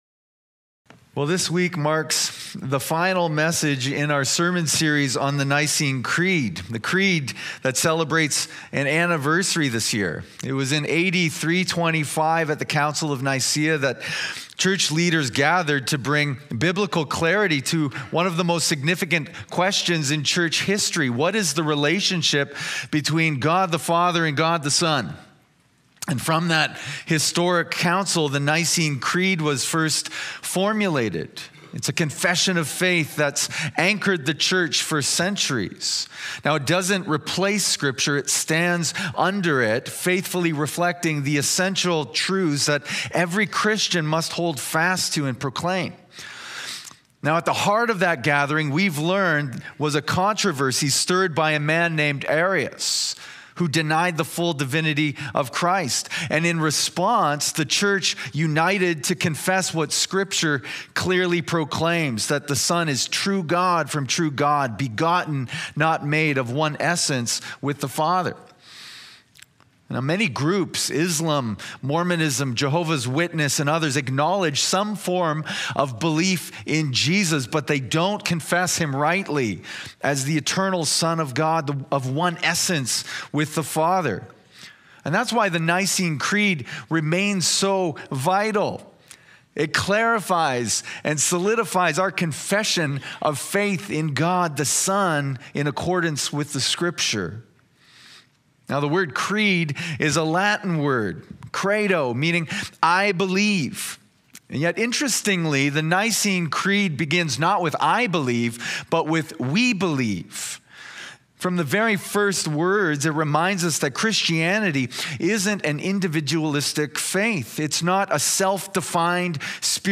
A Podcast of West London Alliance Church featuring the latest Sunday morning sermon.